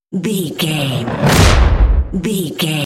Dramatic whoosh to hit trailer
Sound Effects
Fast paced
In-crescendo
Atonal
dark
intense
tension
woosh to hit